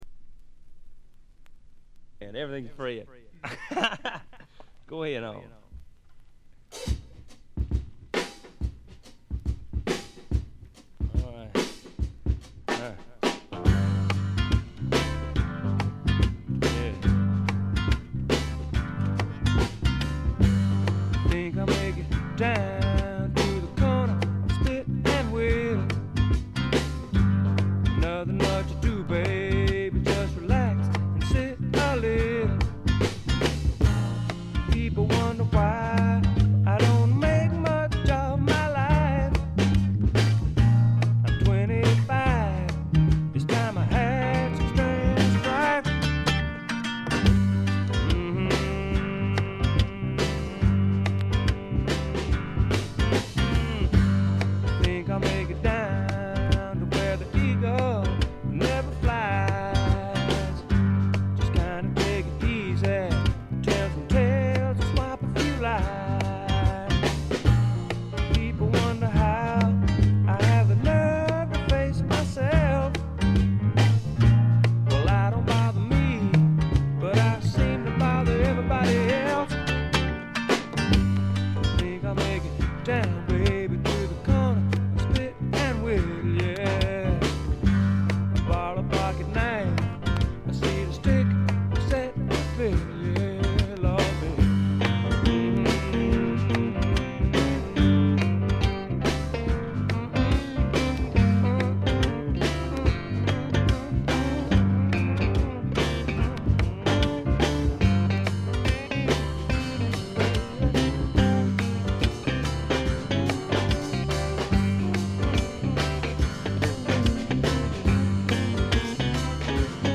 部分試聴ですが、ごくわずかなノイズ感のみ。
あまりナッシュビルぽくないというかカントリーぽさがないのが特徴でしょうか。
試聴曲は現品からの取り込み音源です。
Guitar, Vocals, Piano, Vibes